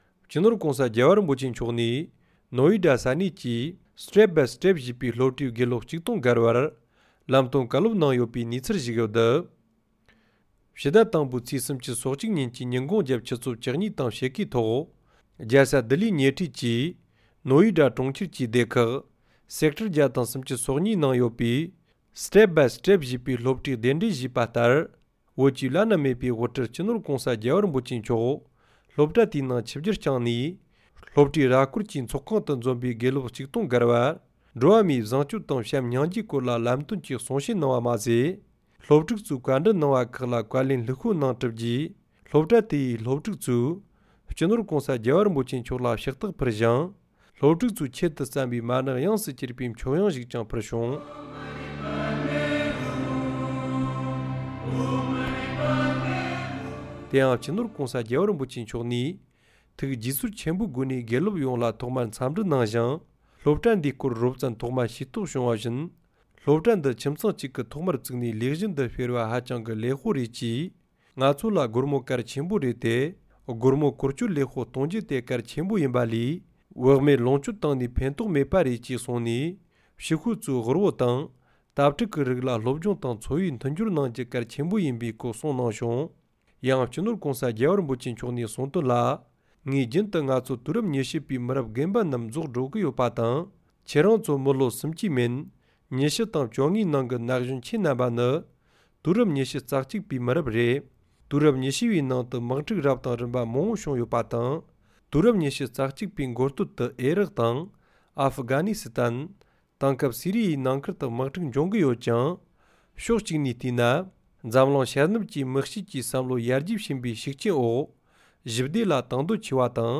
ས་གནས་ནས་བཏང་བའི་གནས་ཚུལ་ལ་གསན་རོགས༎